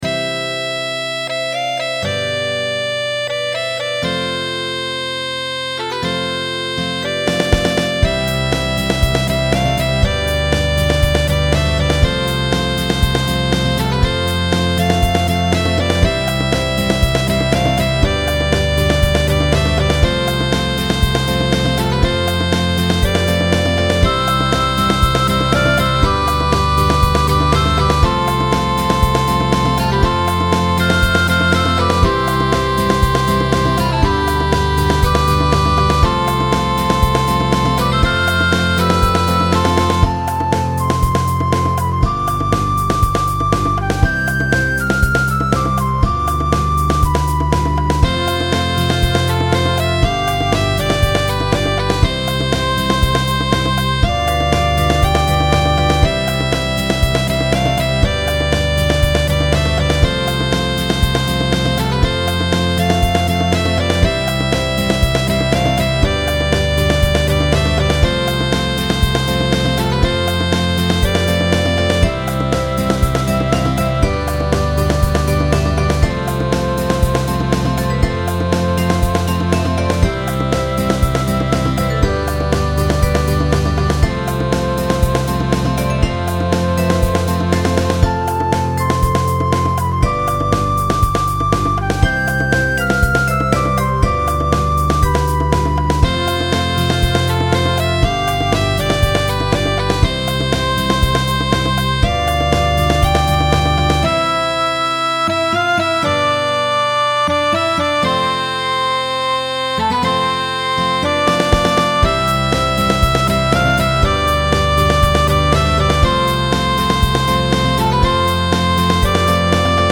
モノラル   またもや悩まされました。フルートの音が美しいです。